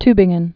(tbĭng-ən, tü-)